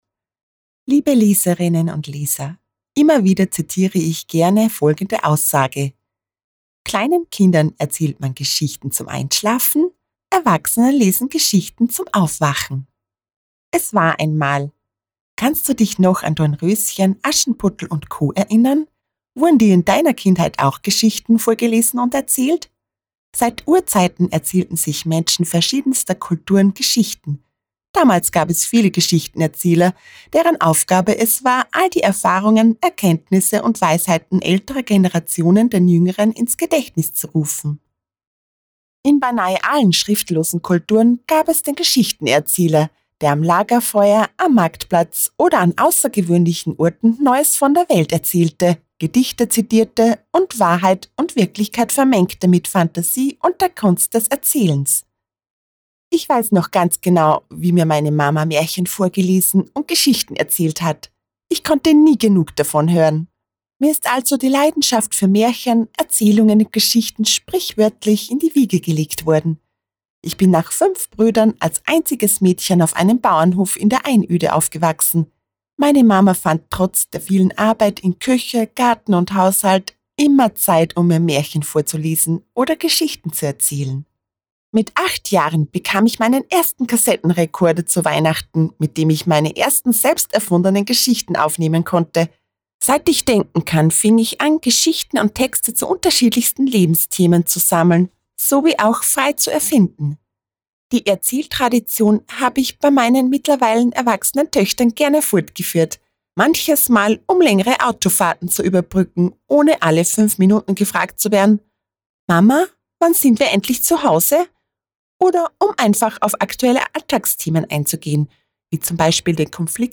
Hörbücher
In meinem Studio arbeite ich ausschließlich mit hochwertigem Equipment und garantiere Dir somit hohe Qualität und top Service.